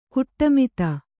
♪ kuṭṭamita